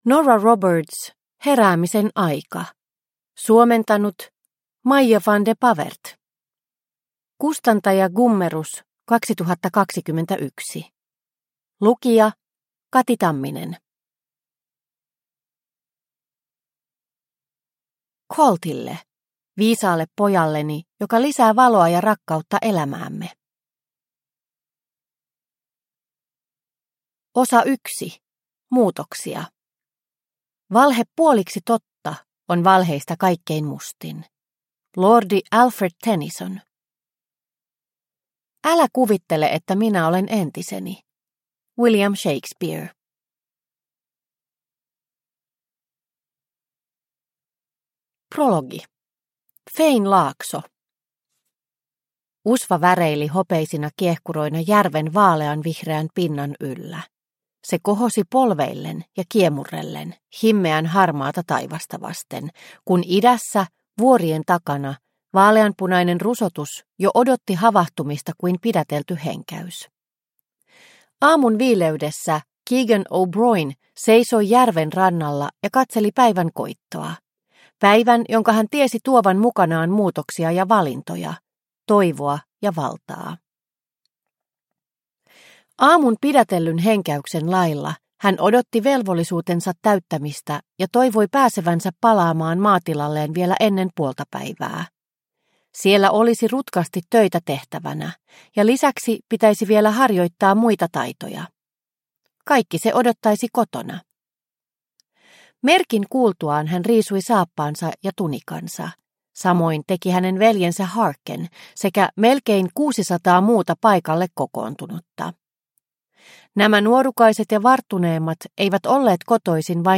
Heräämisen aika – Ljudbok – Laddas ner